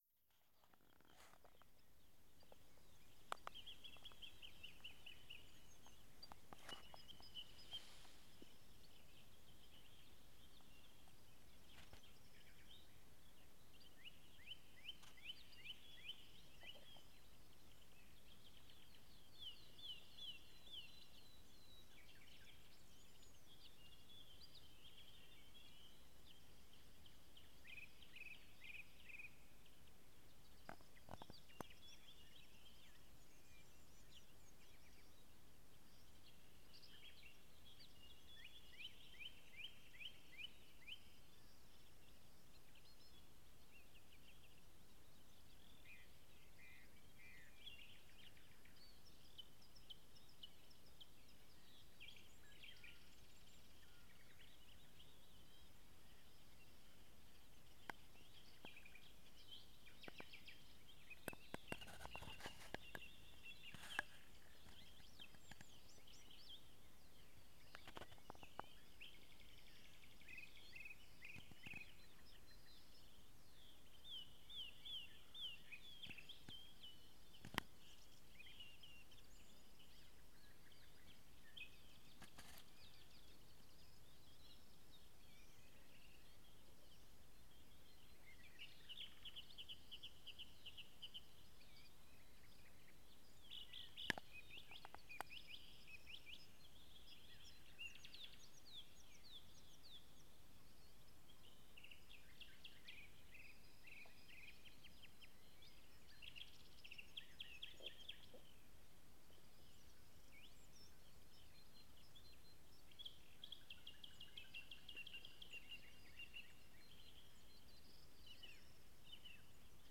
Escuchar el amanecer en las vegas del río Aguasvivas
Si te gusta la tranquilidad, sentarte ante una vega, con huertos, árboles y los pajarillos llamando a sus congéneres... Te ofrecemos esta grabación tomada el pasado 23 de abril en las vegas de Blesa.